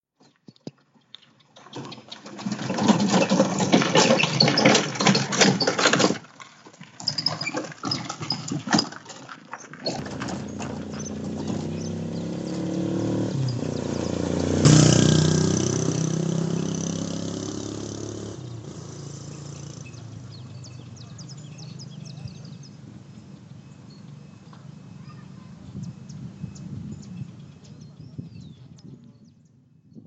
描述：唱歌在Plaza de los Novios的福音书在圣玛尔塔，哥伦比亚
标签： 歌曲 哥伦比亚 街道
声道立体声